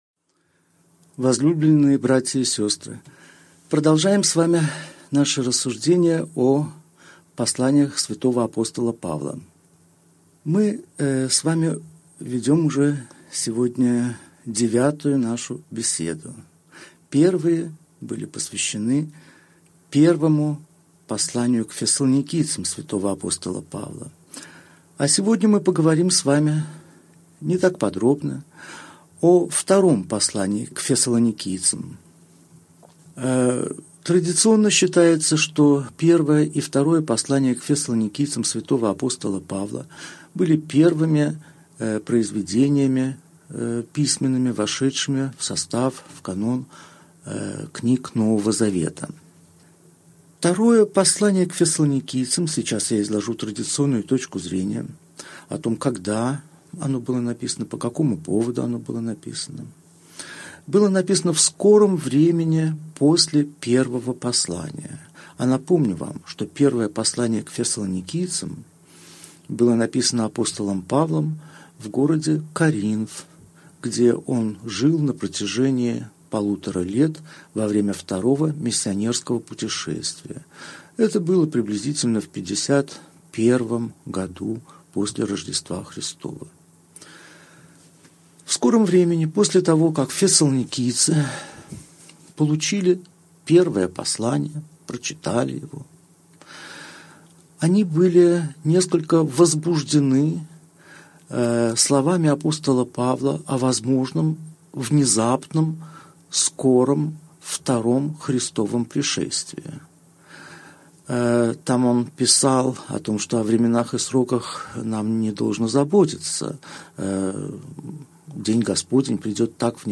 Аудиокнига Беседа 9. Второе послание к Фессалоникийцам. История создания | Библиотека аудиокниг